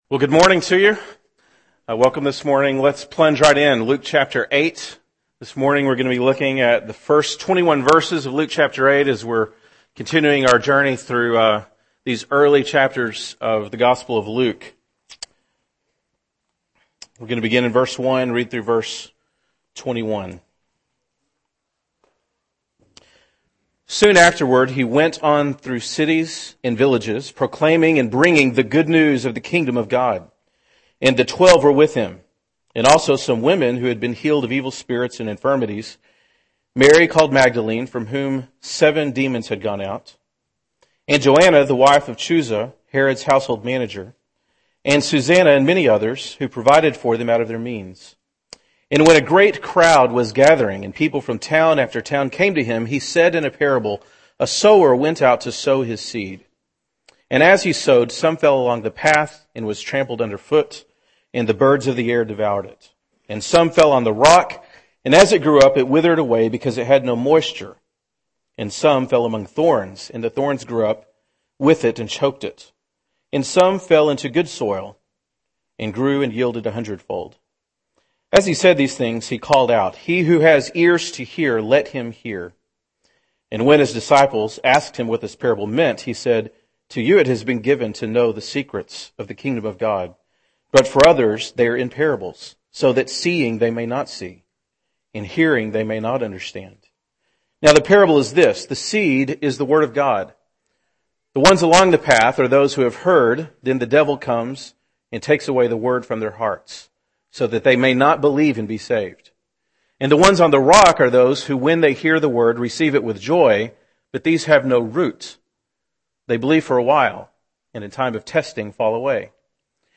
February 26, 2012 (Sunday Morning)